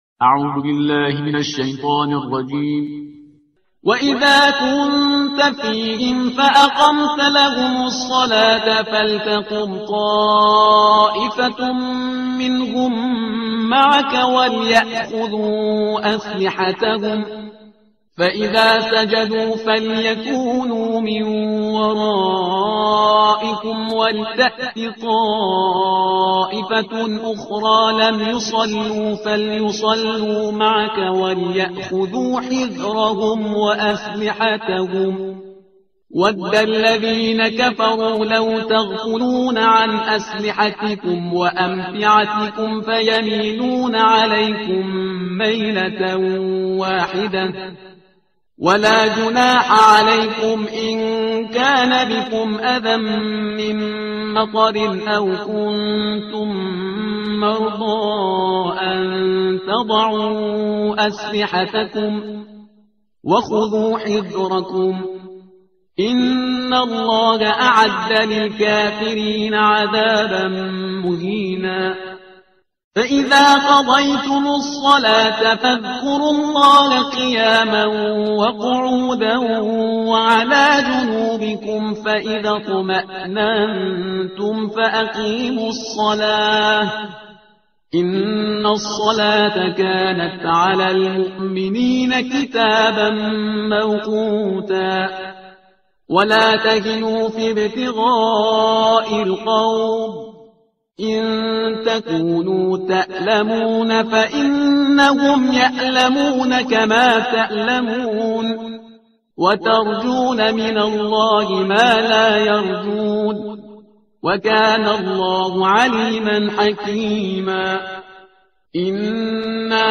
ترتیل صفحه 95 قرآن – جزء پنجم